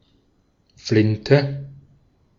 Ääntäminen
Synonyymit batterie carabine rifle pétoire Ääntäminen France: IPA: [fy.zi] Haettu sana löytyi näillä lähdekielillä: ranska Käännös Ääninäyte Substantiivit 1.